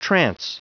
Prononciation du mot trance en anglais (fichier audio)
Prononciation du mot : trance